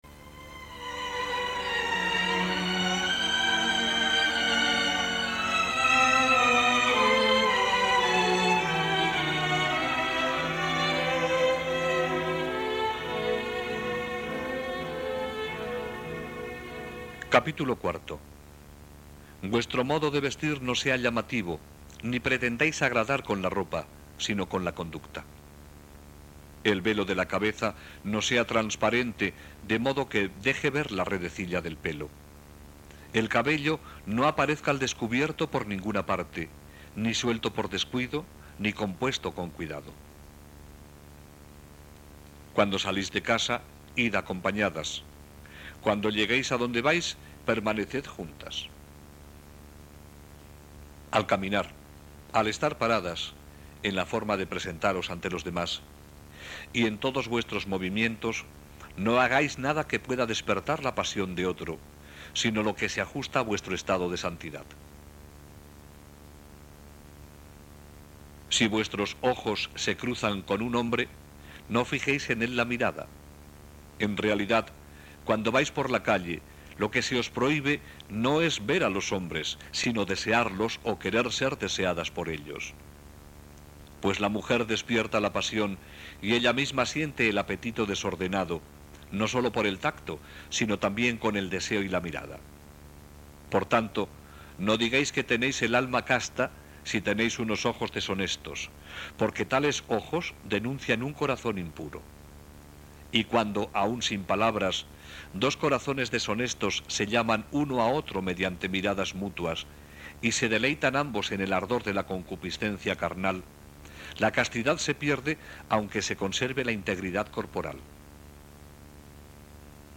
Regla de san Agustín en audio. Versión femenina. Voz de hombre. Español. MP3.